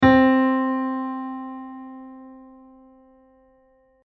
For example, if you hear
this note, could you sing it back?
If you have a tuner, you can also use it to check if you hit the note “C”.
Ton-C.mp3